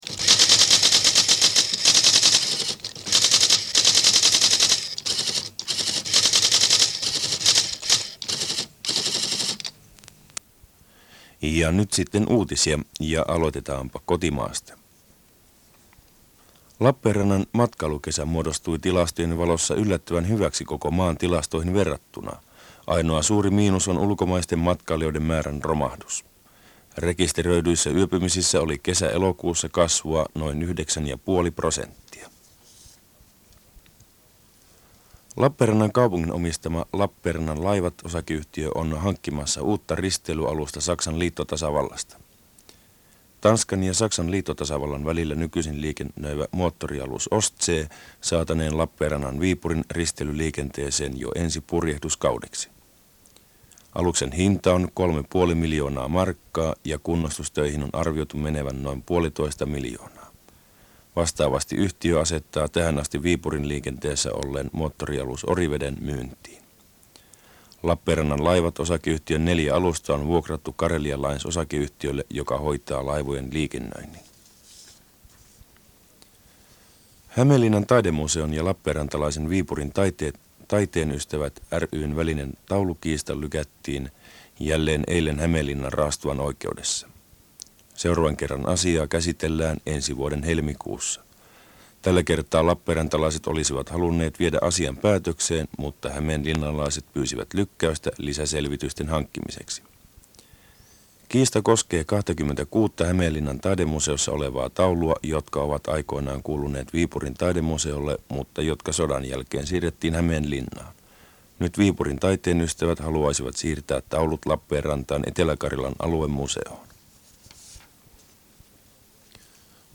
Saimaan Aaltojen uutistunnus ja uutiset vuonna 1986.
Saimaan-Aallot-uutiset-1986.mp3